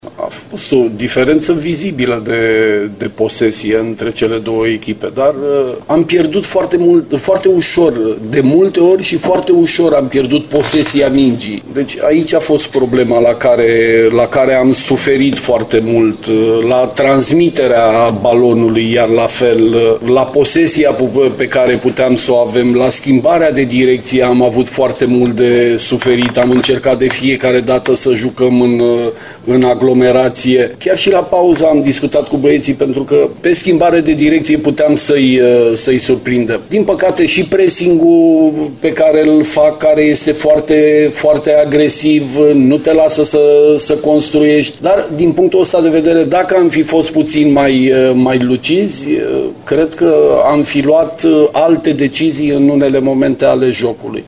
Selecționerul reprezentativei noastre a vorbit și despre unde crede că s-a greșit în meciul de aseară de pe arena din Ghencea: